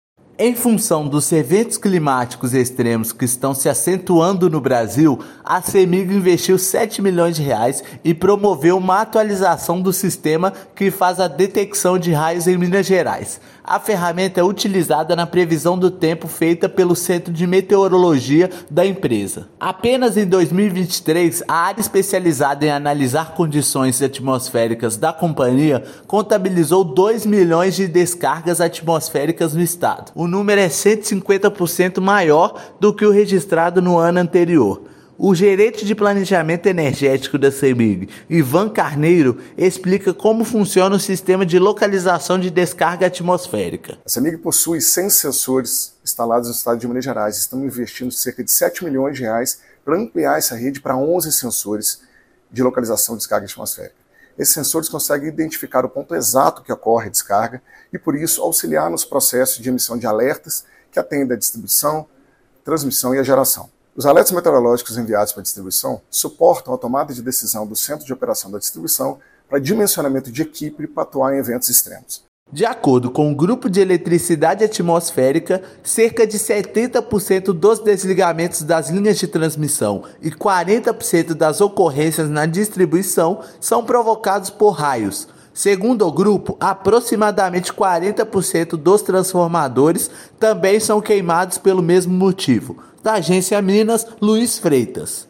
Sistema auxilia as equipes de campo na identificação de problemas causados por descargas atmosféricas. Ouça matéria de rádio.